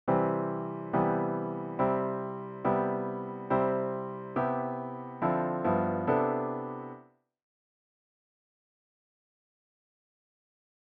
In this next idea, we 'soften' major triad voicings into major 7th shapes. Please examine the pitches in 'C' major.
We've the root to major 7th interval in the two lowest pitches.